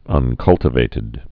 (ŭn-kŭltə-vātĭd)